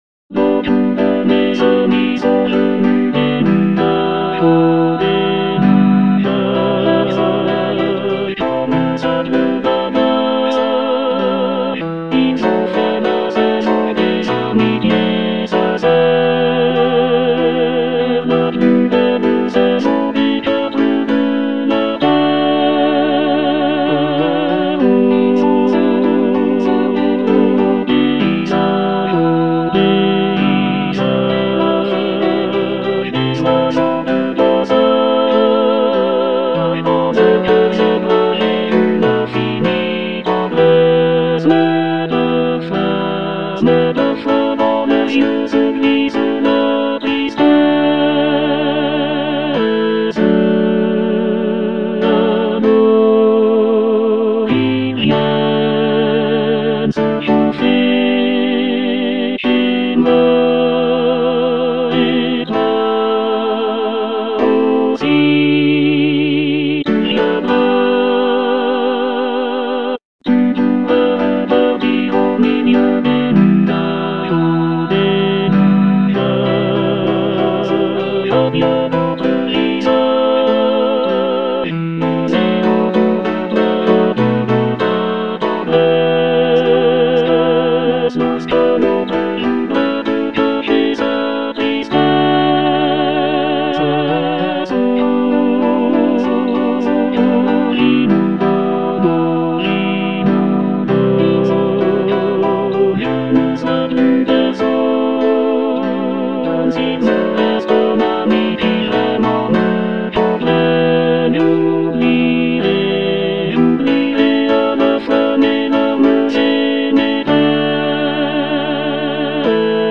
Tenor II (Emphasised voice and other voices)
a charming piece for choir